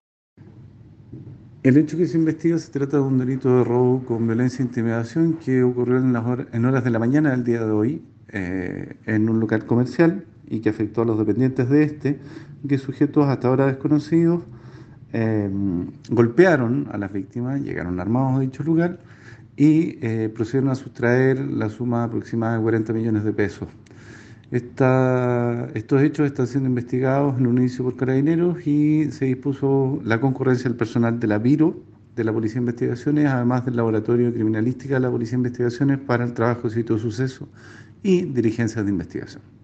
Fiscal Daniel Soto..